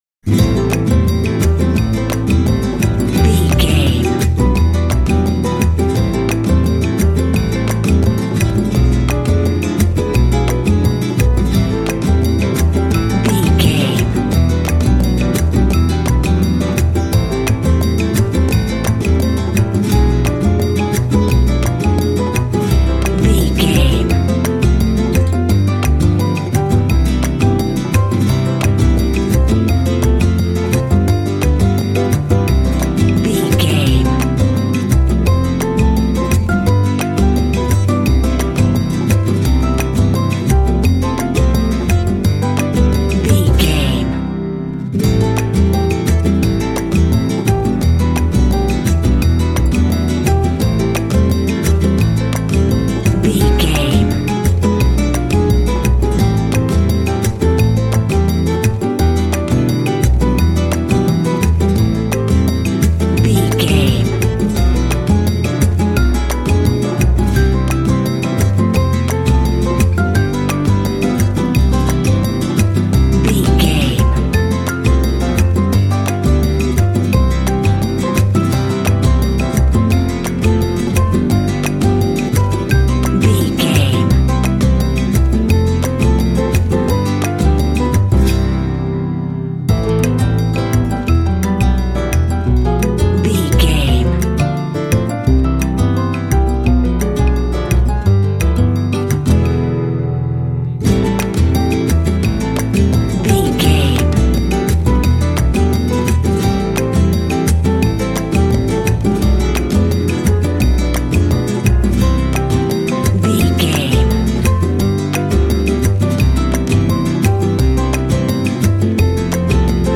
Aeolian/Minor
sexy
smooth
sensual
acoustic guitar
piano
percussion
double bass
latin
flamenco
mambo
rhumba